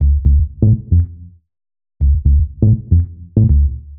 pdh_120_bass_tease_Dmin.wav